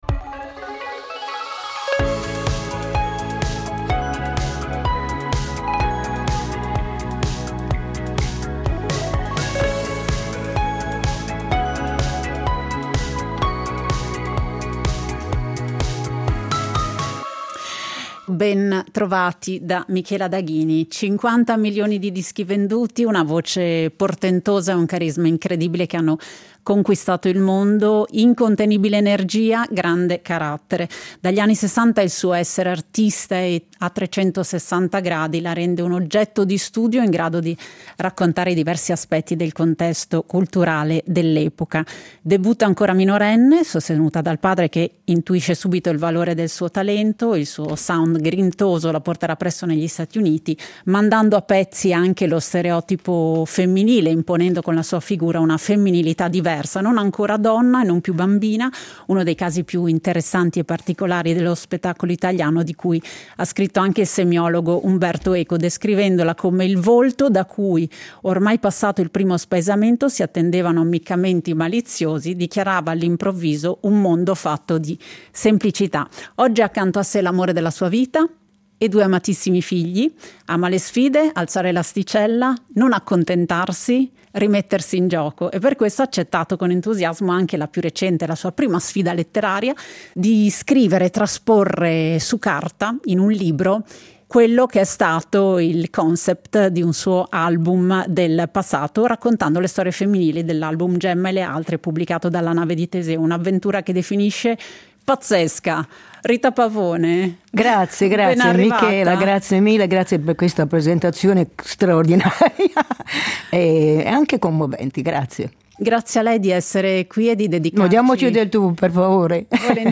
A colloquio